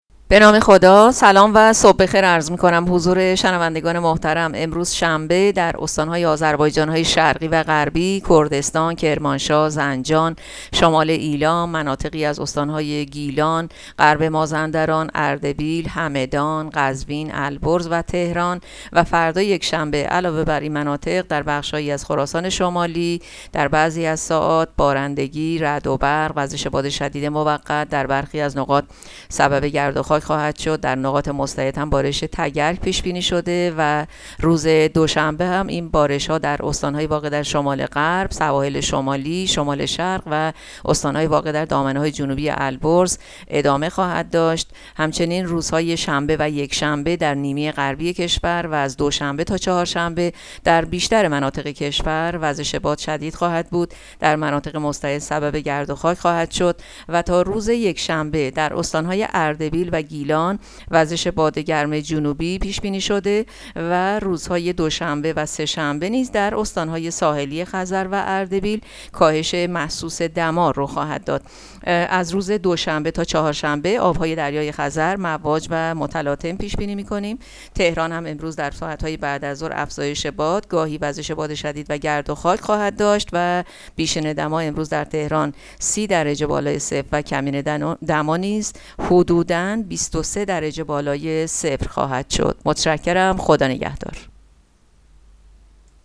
گزارش رادیو اینترنتی پایگاه‌ خبری از آخرین وضعیت آب‌وهوای ششم اردیبهشت؛